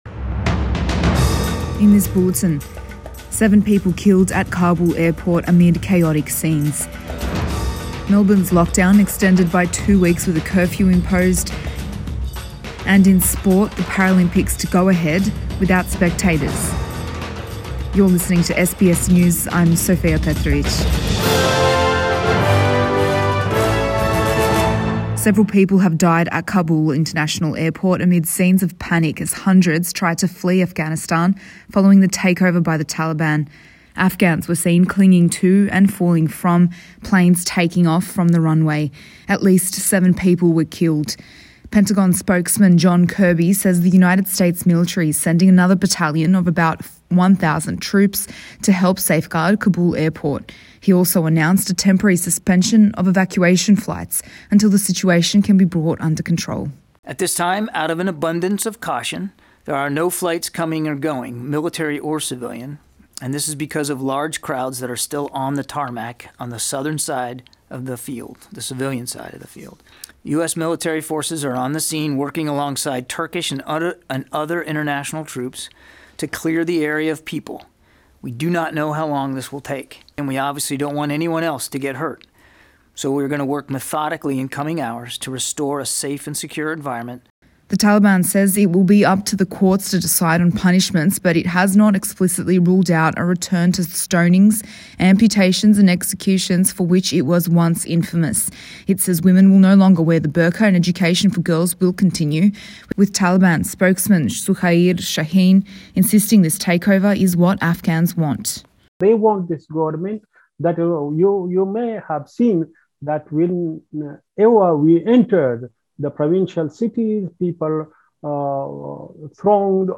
AM bulletin 17 August 2021